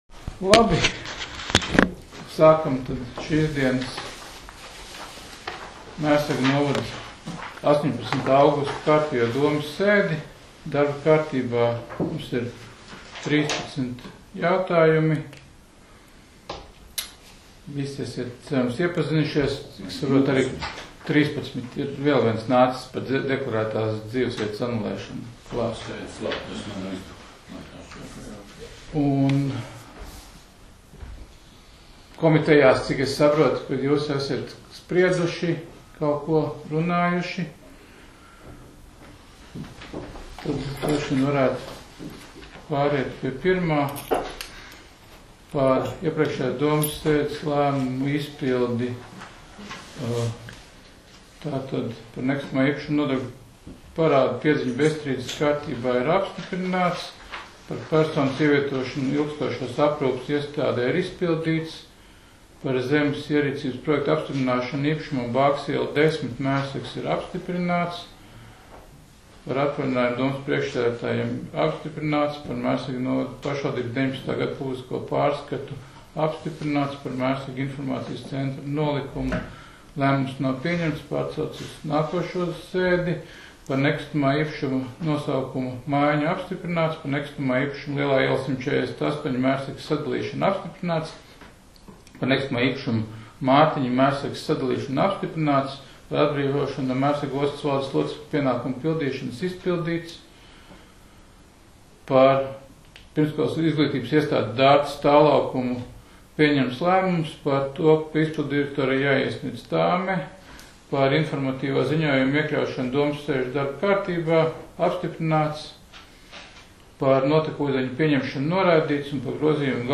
Mērsraga novada domes sēde 18.08.2020.